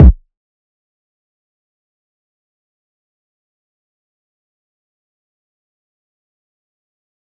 DMV3_Kick 2.wav